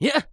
CK蓄力07.wav
CK蓄力07.wav 0:00.00 0:00.34 CK蓄力07.wav WAV · 29 KB · 單聲道 (1ch) 下载文件 本站所有音效均采用 CC0 授权 ，可免费用于商业与个人项目，无需署名。
人声采集素材/男2刺客型/CK蓄力07.wav